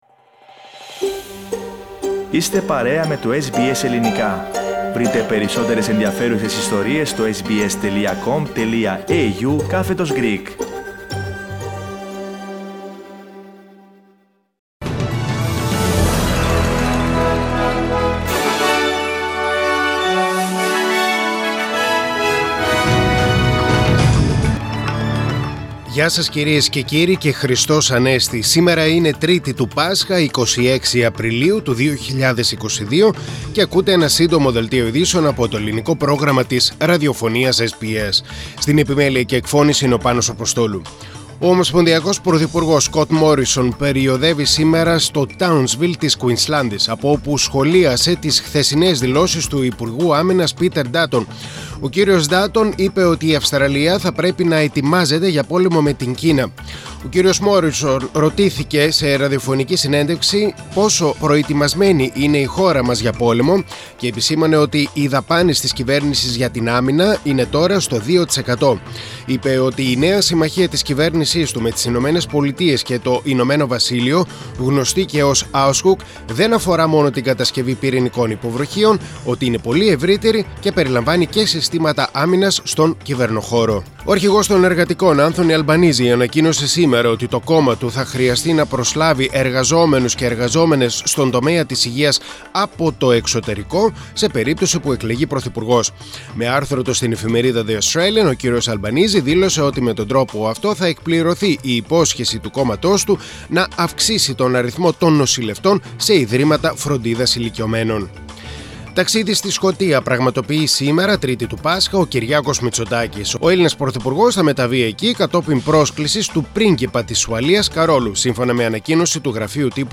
Σύντομο Δελτίο Eιδήσεων στα Ελληνικά.